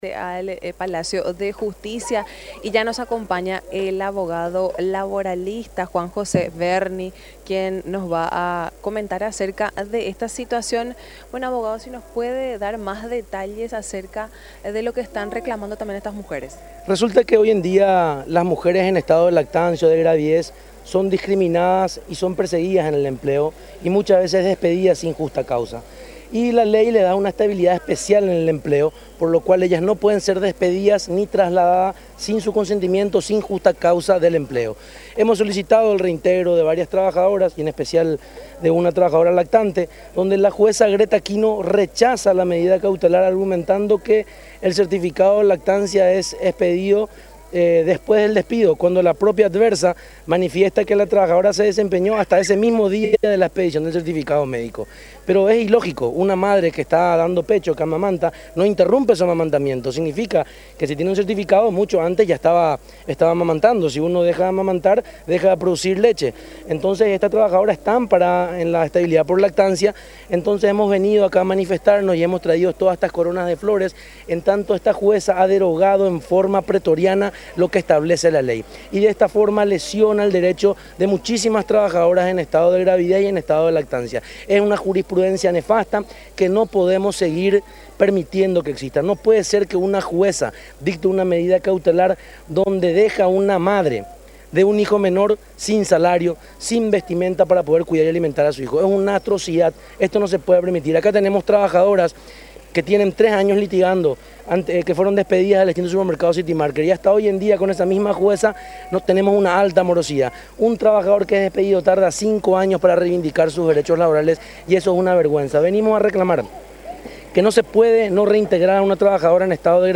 abogado laboralista
conversación